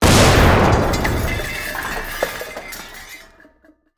CosmicRageSounds / wav / general / combat / weapons / rocket / metal2.wav
metal2.wav